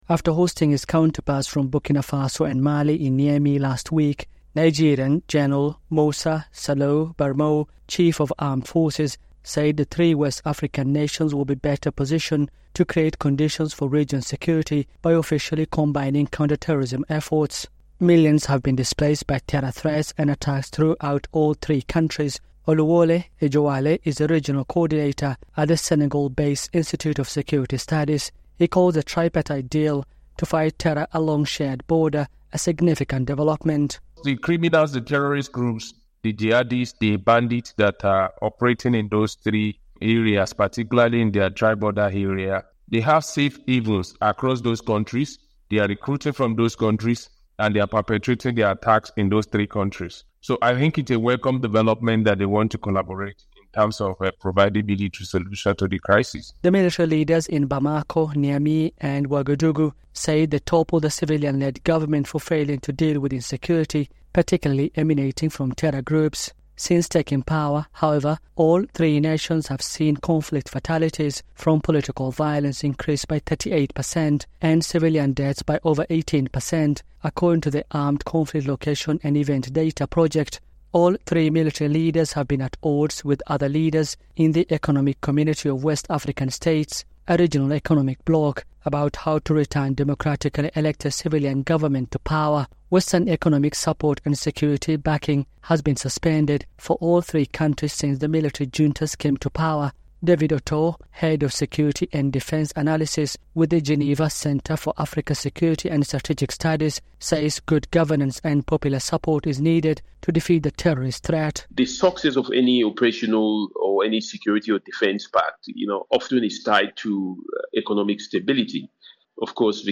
reports from Nairobi.